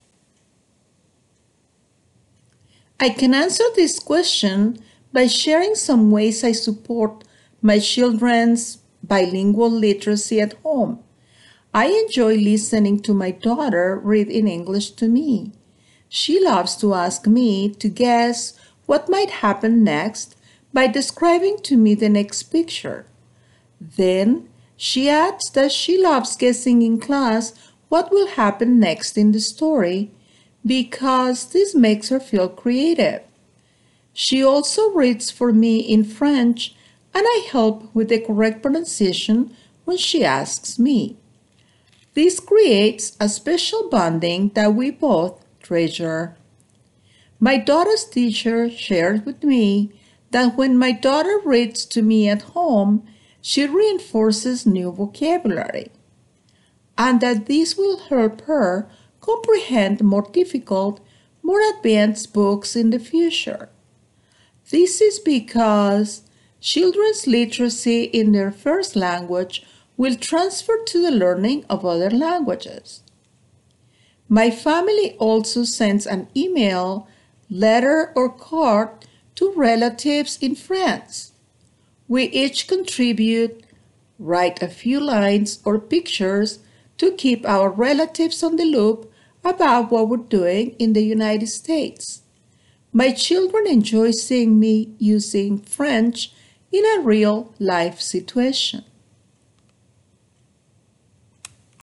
[Note: In the transcript below, ellipses indicate that the speaker paused.]
The response effectively communicates clear and logically sequenced ideas delivered with a consistent flow of speech, few pauses, intelligible pronunciation, and appropriate intonation.
Examples of such errors include some inconsistency in the flow of speech and few pauses.